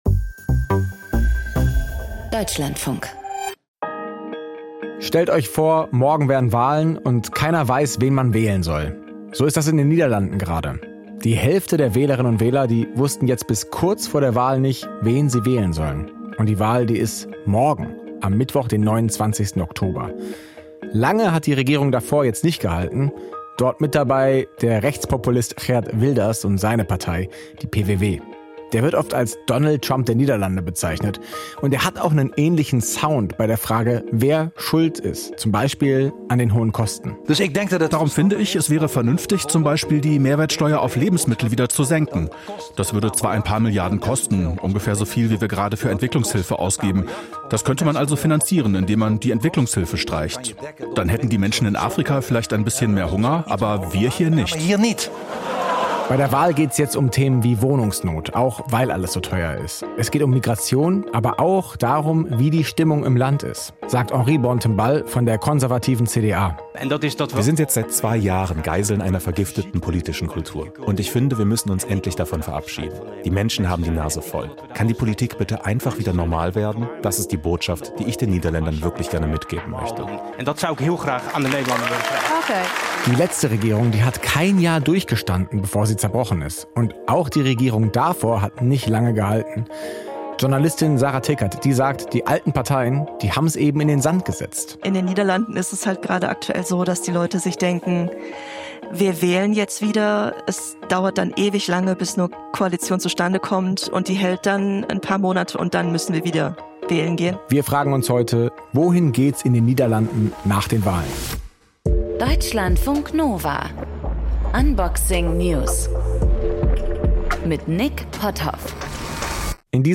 Gesprächspartnerin